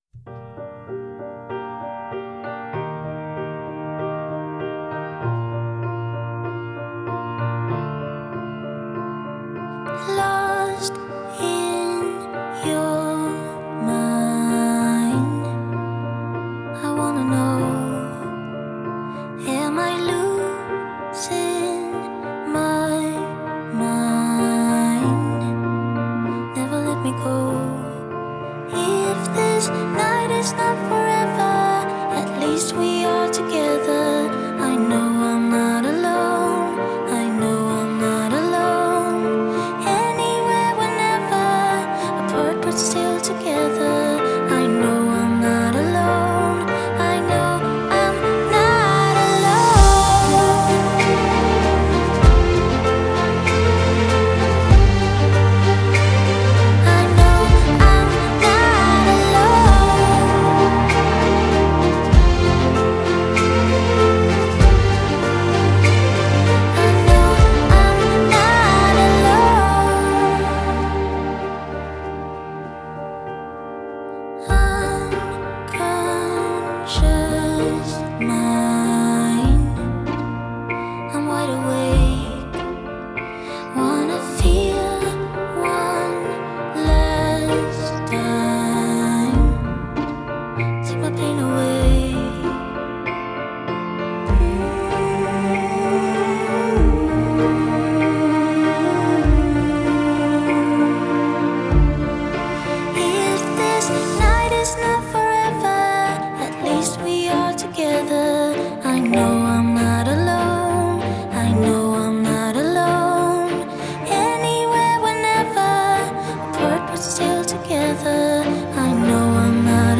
Жанры: EDM, электро-хаус, прогрессив-хаус,
даунтемпо, дип-хаус